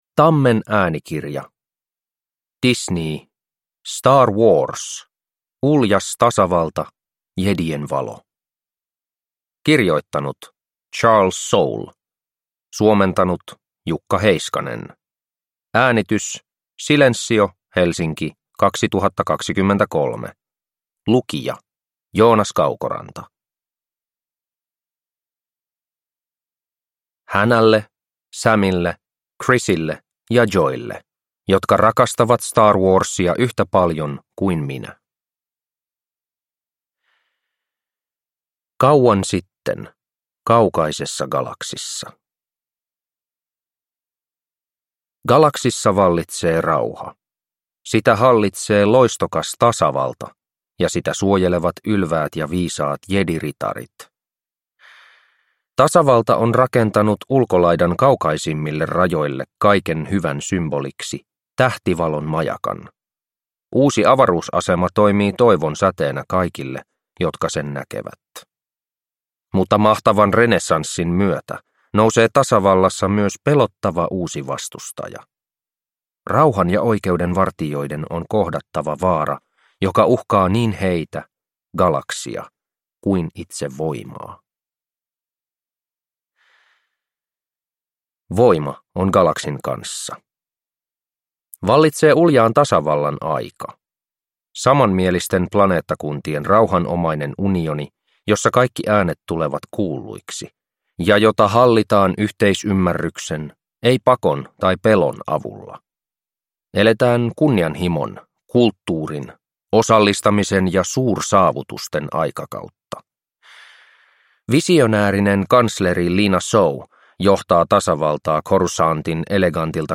Jedien valo – Ljudbok – Laddas ner